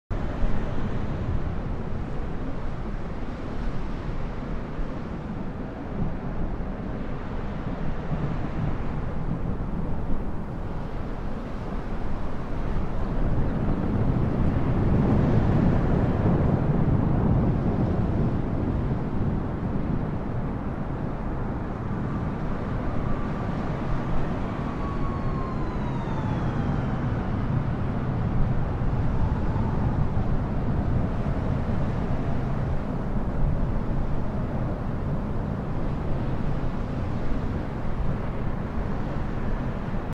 bad weather in all cities sound effects free download